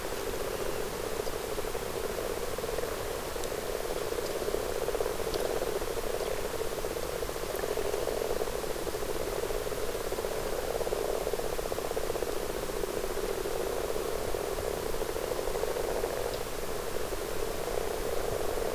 rana_temporaria.wav